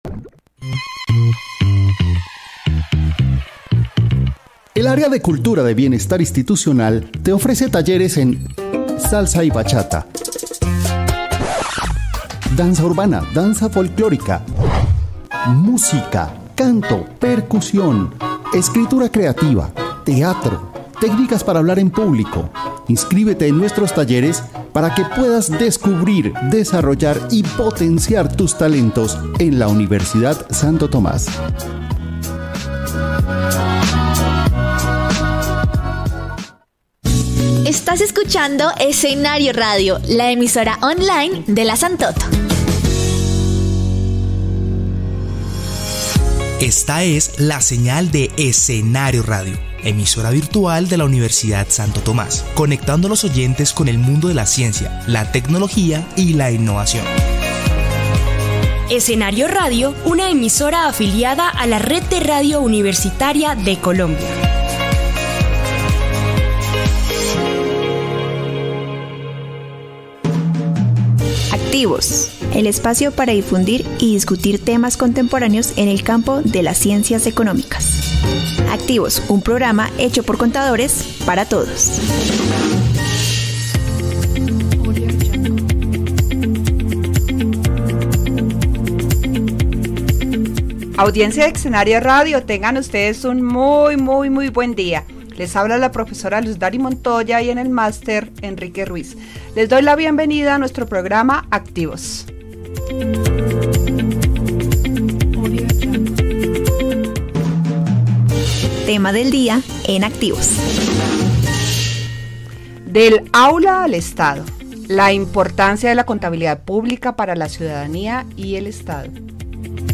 Entrevista al Contador General de la Nación en la emisora Escenario radio (USTA)
entrevista-al-contador-general-de-la-nacion-en-la-emisora-escenario-radio-usta-